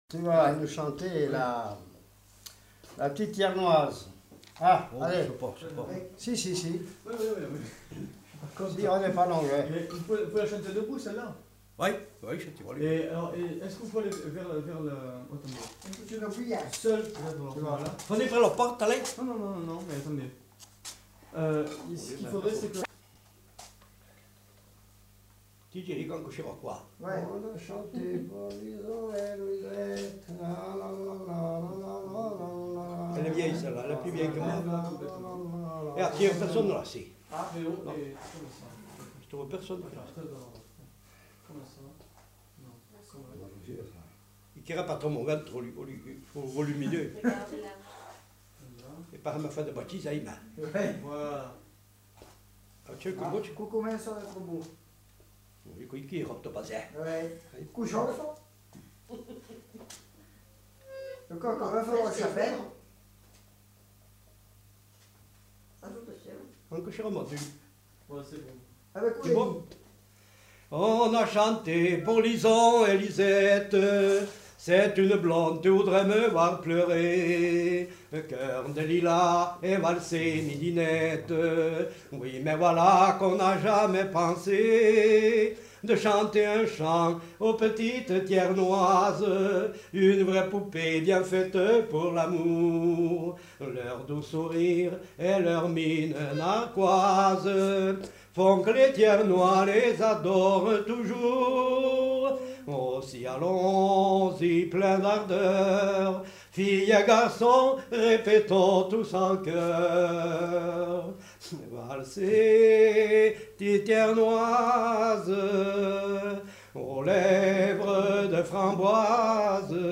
Aire culturelle : Auvergne
Lieu : Peschadoires
Genre : chant
Effectif : 1
Type de voix : voix d'homme
Production du son : chanté ; fredonné